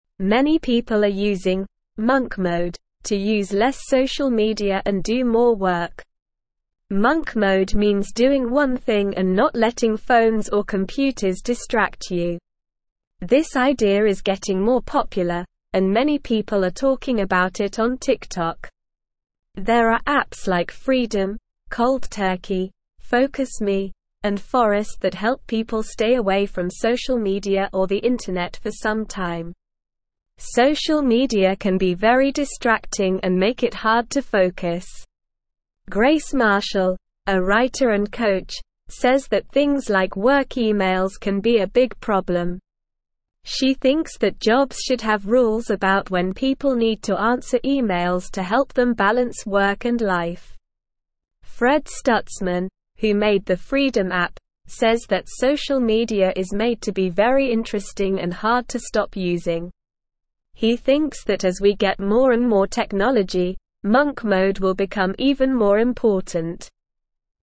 Slow
English-Newsroom-Beginner-SLOW-Reading-Monk-Mode-Using-Less-Social-Media-Doing-More-Work.mp3